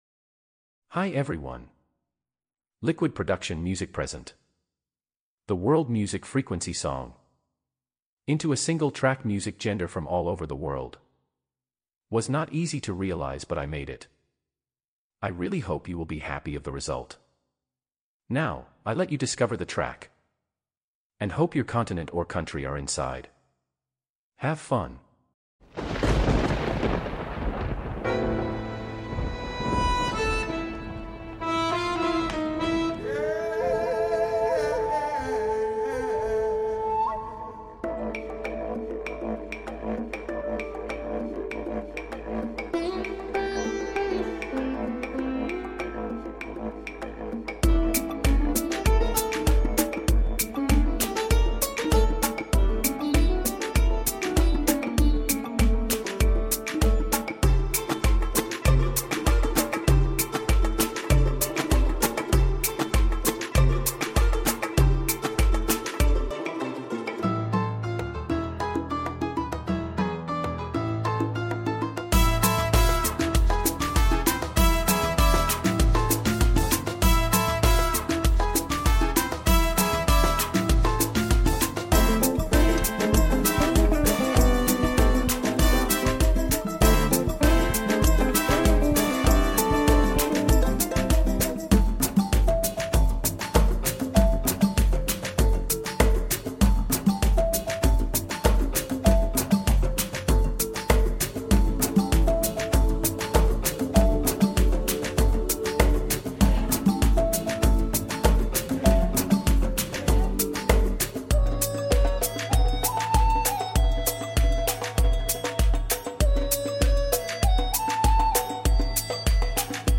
into a single track music gender from all over the world